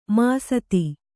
♪ māsati